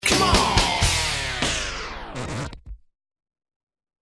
Efecto musical de final de un vinilo
Sonidos: Especiales
Sonidos: Fx web